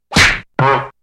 golpes cartoon quilombo 03